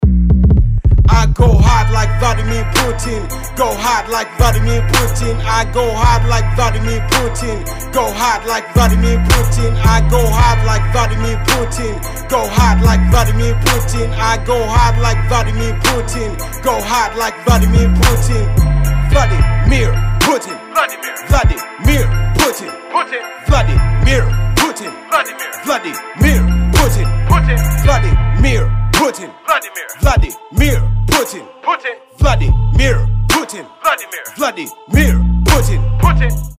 • Качество: 320, Stereo
рэп